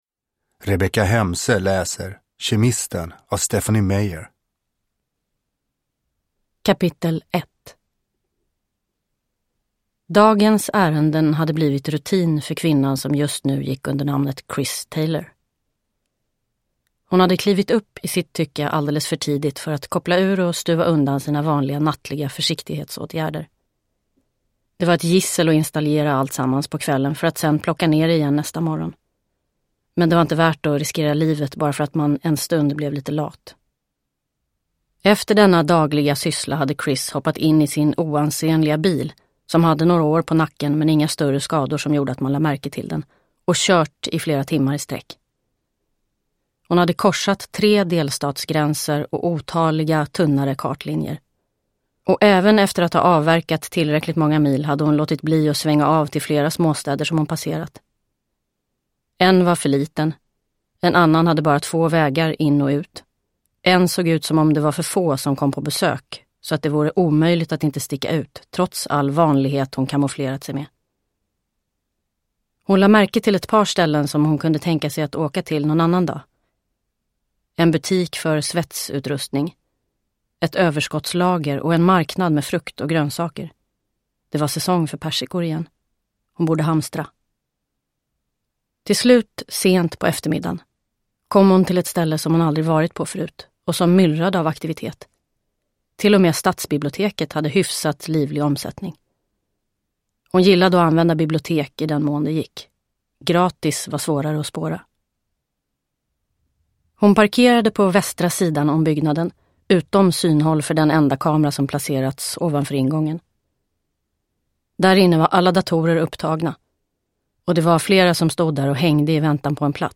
Kemisten – Ljudbok – Laddas ner
Uppläsare: Rebecka Hemse